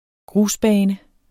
Udtale [ ˈgʁus- ]